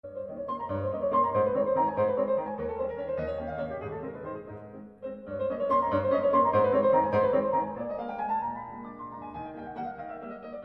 Virtual ambisonic rendering corresponding to the movies in Table 7.
Train yourself with the reference file first (single source r=0%) if you have not already done so, in order to really imagine that the source is drawing a circular (anti-clock wise), horizontal trajectory around you.